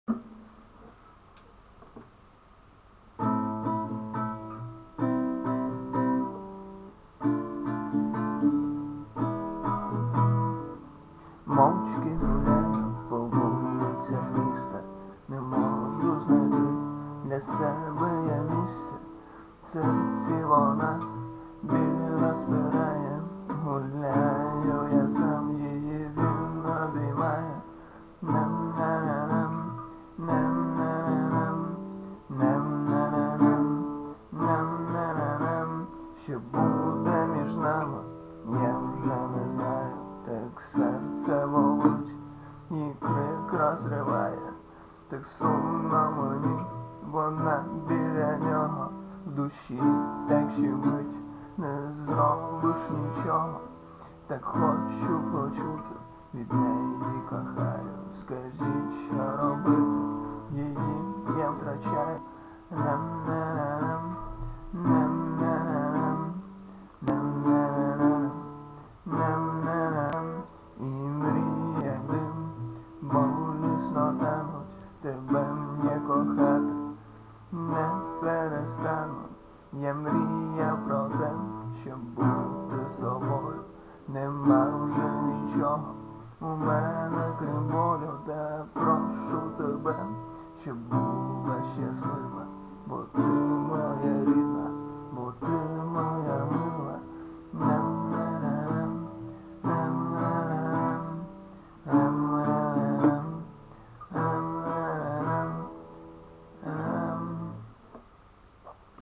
Жахливий запис, кращого нема.mp3